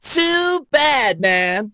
poco_kill_01.wav